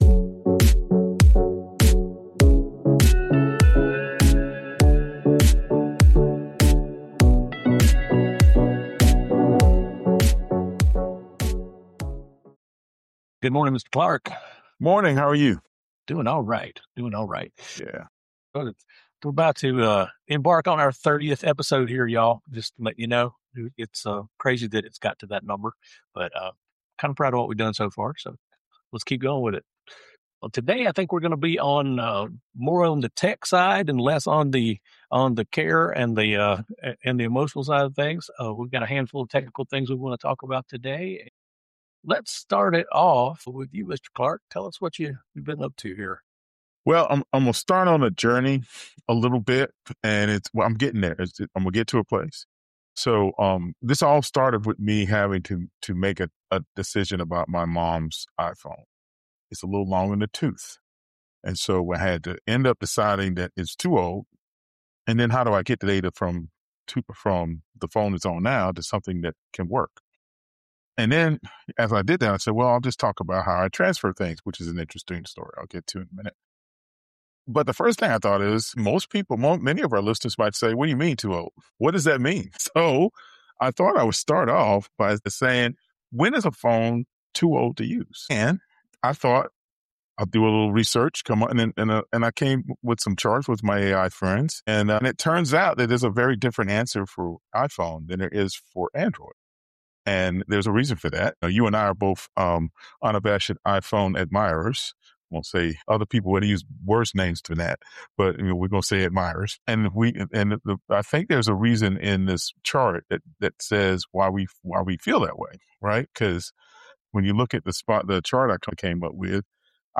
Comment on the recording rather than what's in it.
The conversation starts with a surprisingly common question: how old is too old for a phone?